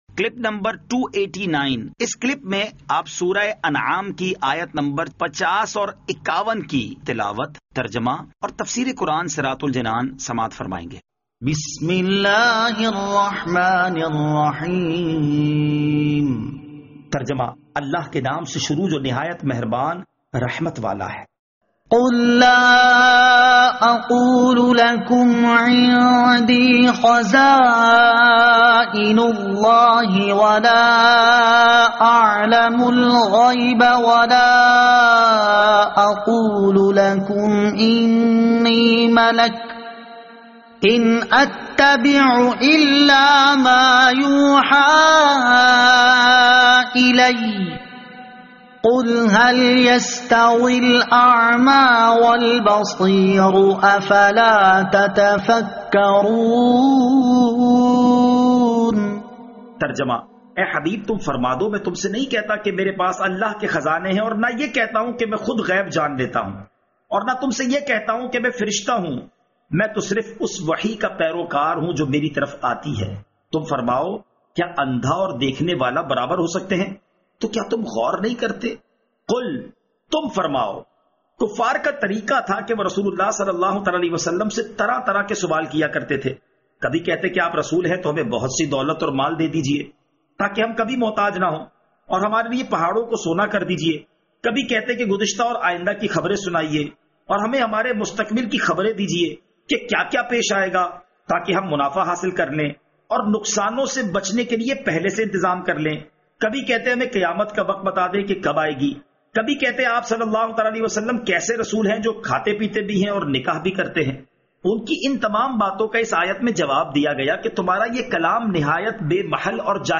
Surah Al-Anaam Ayat 50 To 51 Tilawat , Tarjama , Tafseer
2021 MP3 MP4 MP4 Share سُوَّرۃُ الأنعام آیت 50 تا 51 تلاوت ، ترجمہ ، تفسیر ۔